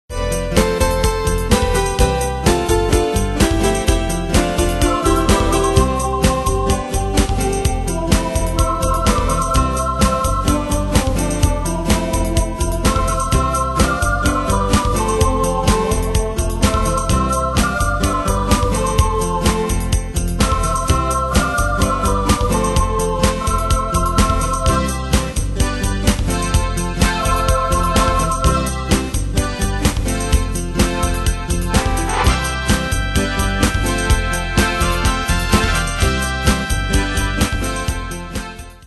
Style: PopAnglo Ane/Year: 1997 Tempo: 127 Durée/Time: 3.56
Danse/Dance: Pop Cat Id.
Pro Backing Tracks